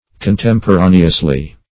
Contemporaneously \Con*tem`po*ra"ne*ous*ly\, adv.